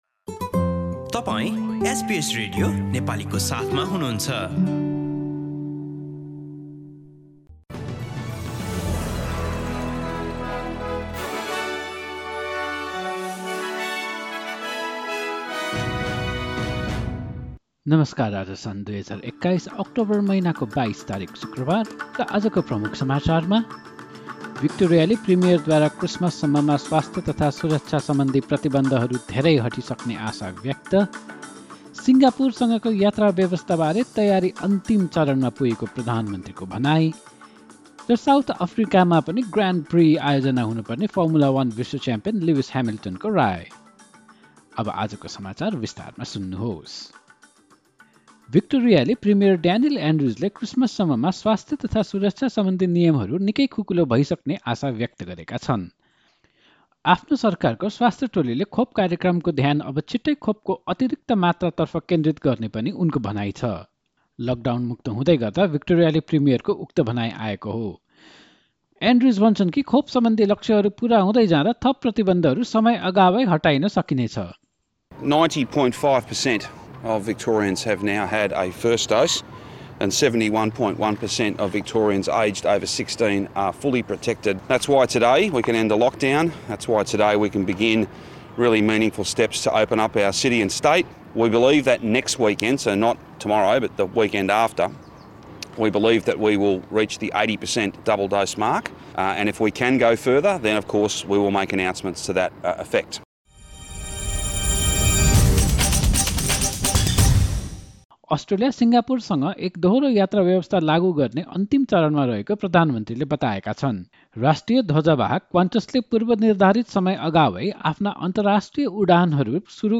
एसबीएस नेपाली अस्ट्रेलिया समाचार: शुक्रवार २२ अक्टोबर २०२१